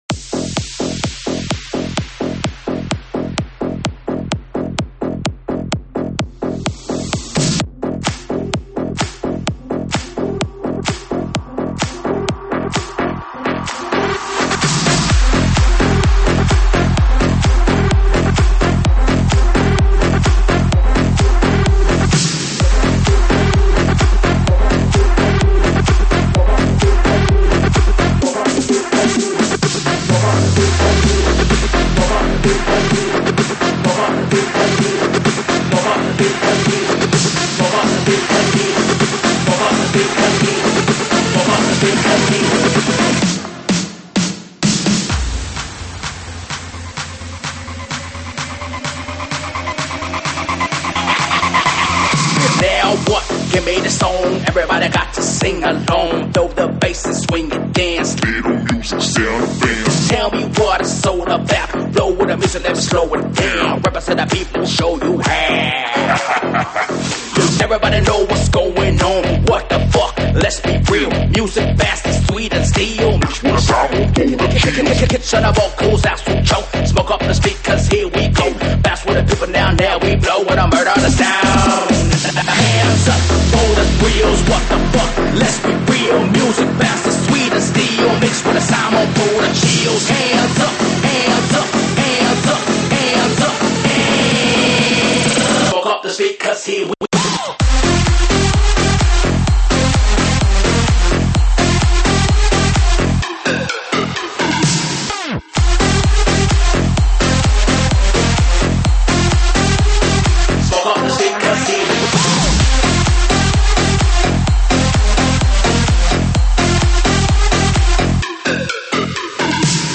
栏目： 慢摇舞曲